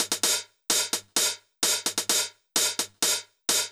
CLF Beat - Mix 19.wav